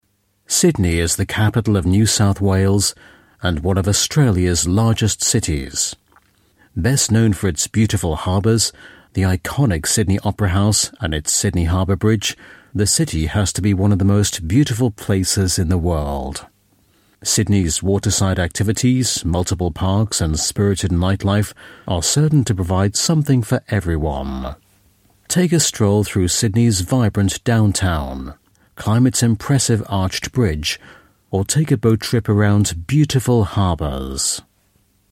Native Speaker
Englisch (UK)
Dokus